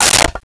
Plastic Plate 01
Plastic Plate 01.wav